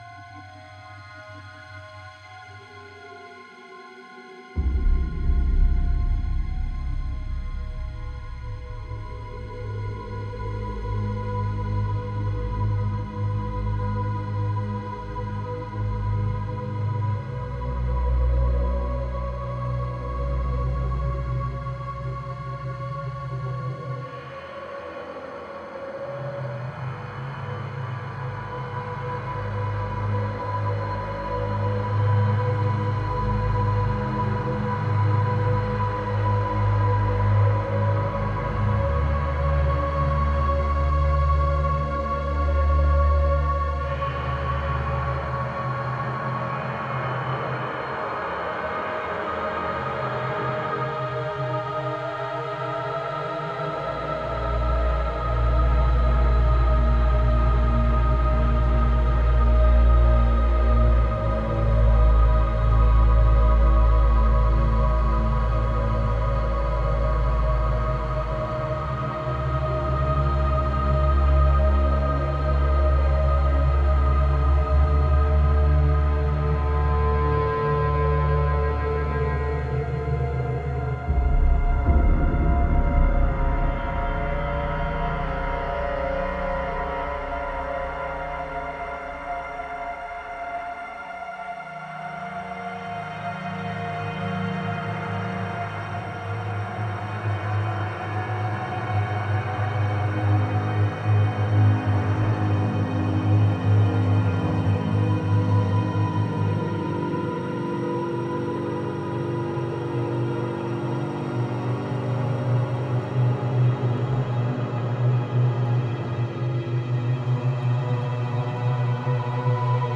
/ 01 - Ambience / Ambience 1 - The Entrance -... 31 MiB Raw Permalink History
Ambience 1 - The Entrance - (Loop).wav